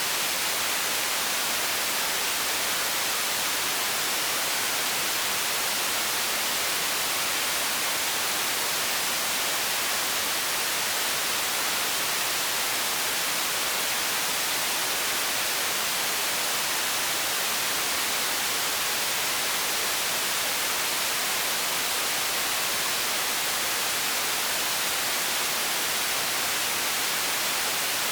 rain_level_4.ogg